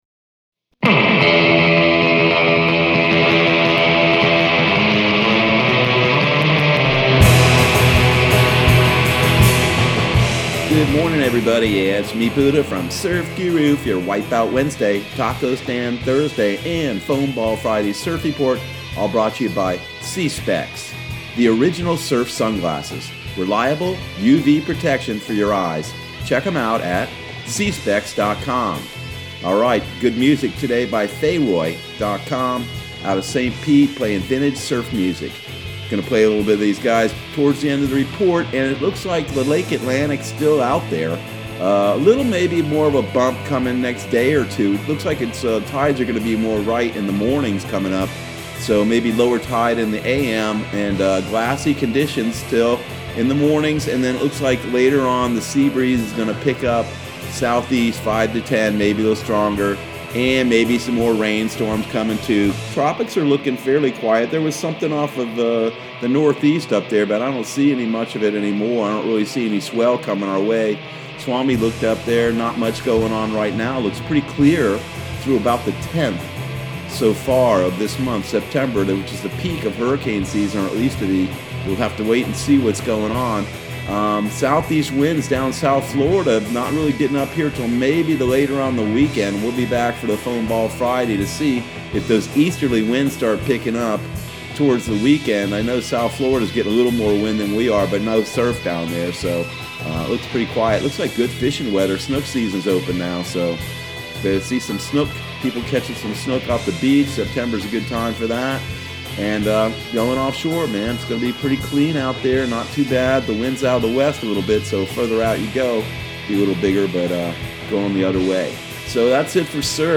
Surf Guru Surf Report and Forecast 09/02/2020 Audio surf report and surf forecast on September 02 for Central Florida and the Southeast.